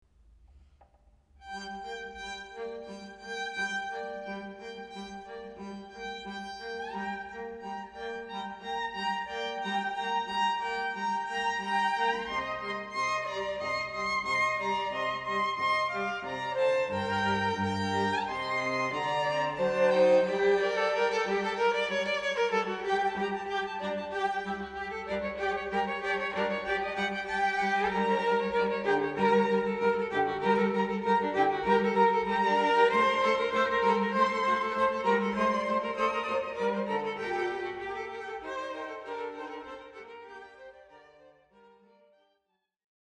Kletzmer
En stråkkvartett från Göteborg